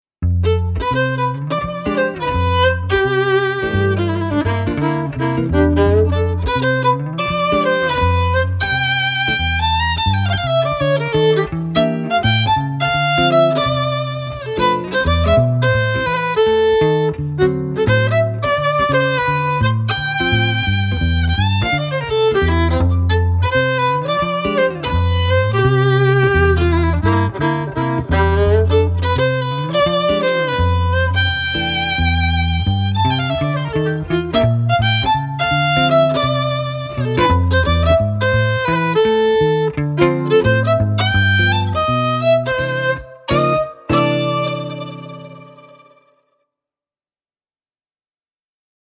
Andante [80-90] melancolie - violon - detente - nuit - ville
detente - nuit - ville